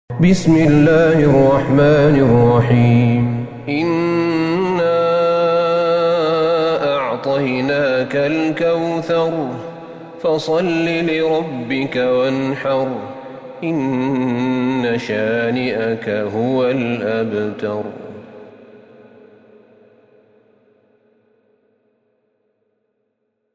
سورة الكوثر Surat Al-Kawthar > مصحف الشيخ أحمد بن طالب بن حميد من الحرم النبوي > المصحف - تلاوات الحرمين